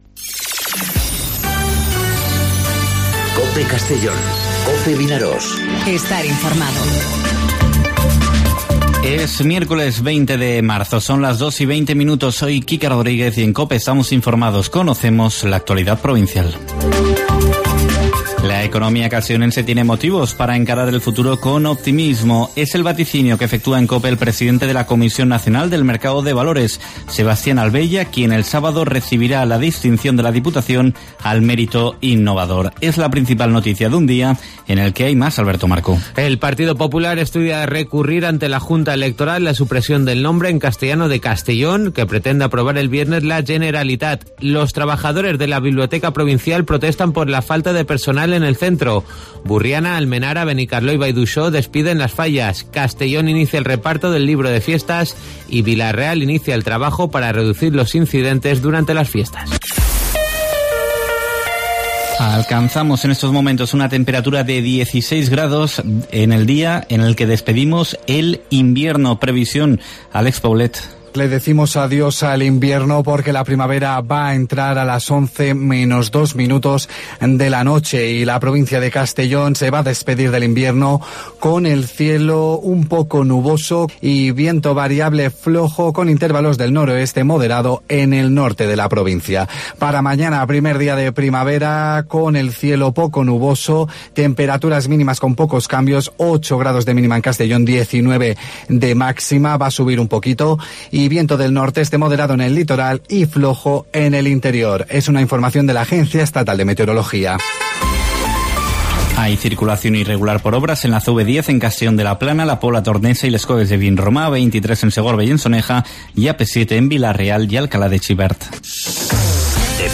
Informativo 'Mediodía COPE' en Castellón (20/03/2019)